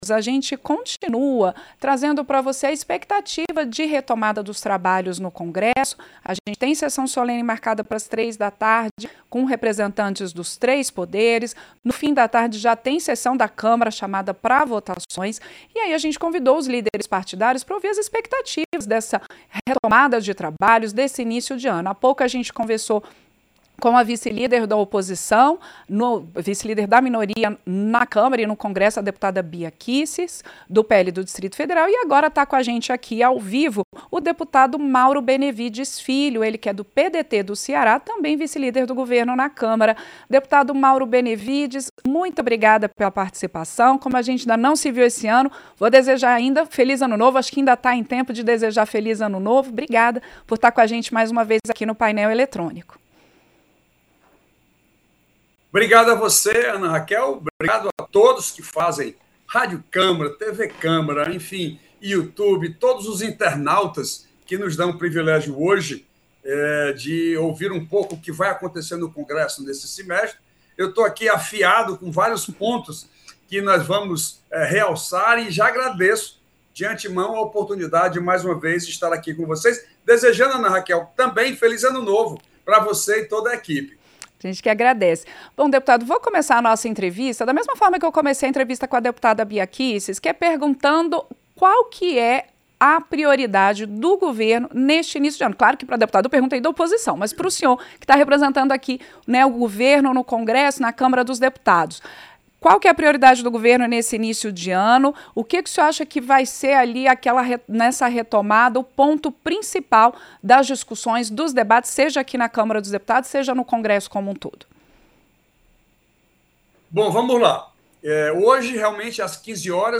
Entrevista - Dep. Mauro Benevides Filho (PDT-CE)